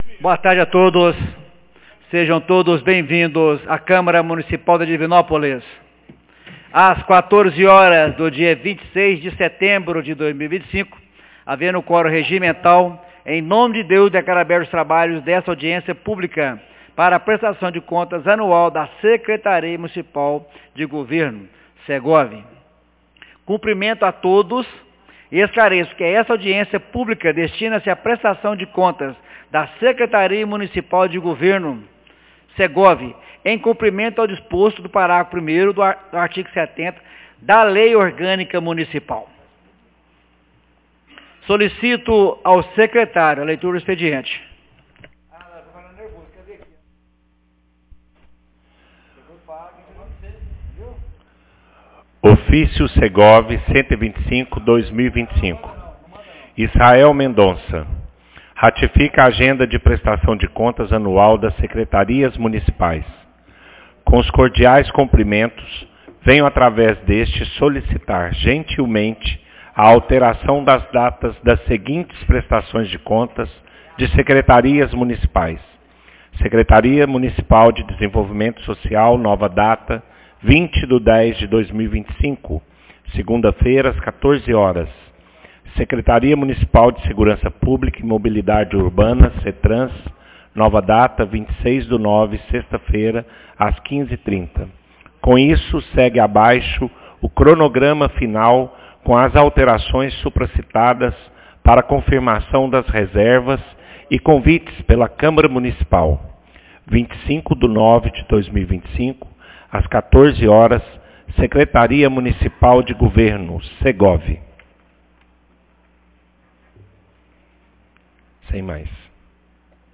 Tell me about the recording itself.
Audiencia publica Prestação de Contas Secretaria do Governo 26 de setembro de 2025